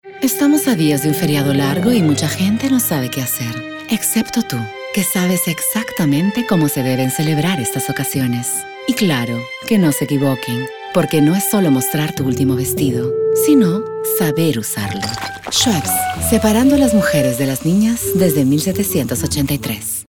Espanhol - América Latina Neutro
Schweppes - Voz Mulher Adulta
Voz Madura